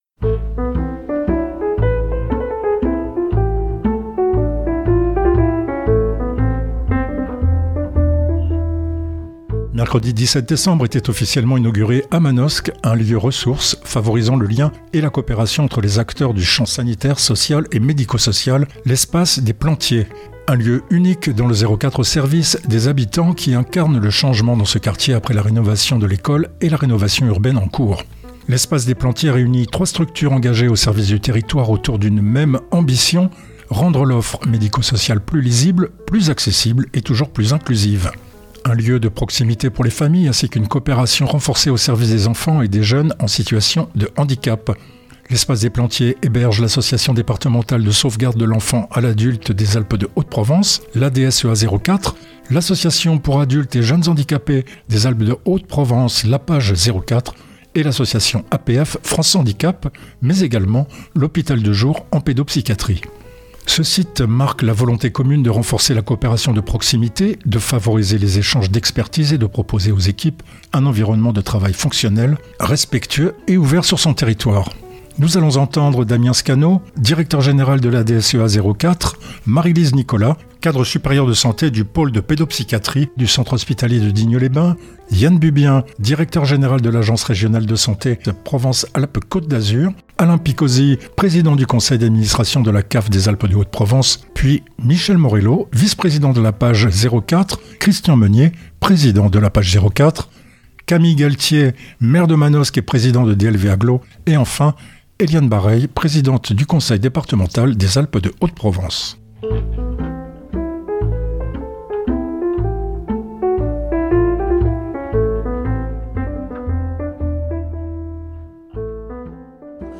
Les interviews :